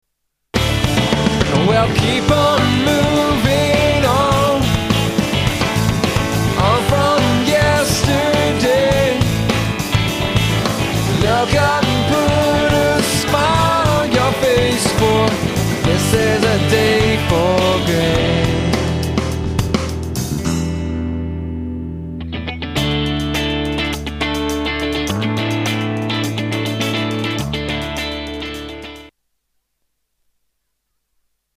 STYLE: Rock